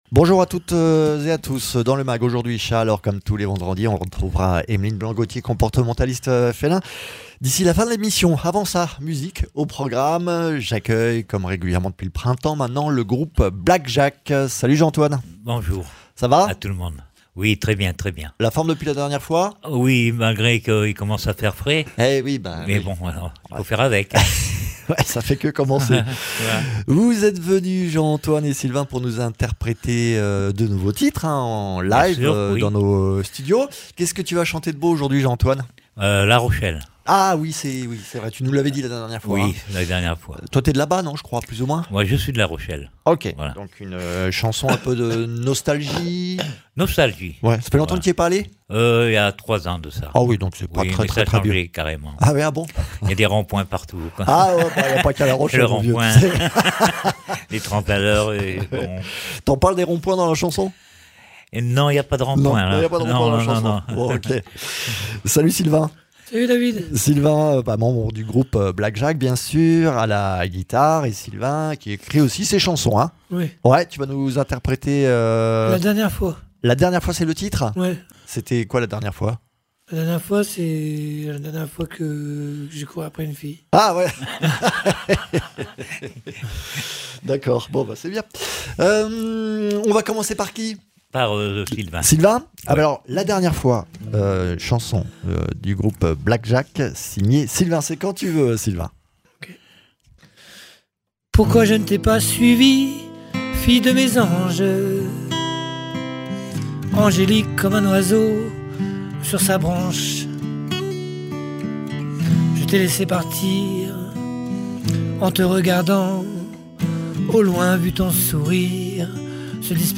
vient nous jouer deux nouveaux morceaux en studio